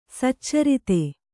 ♪ saccarite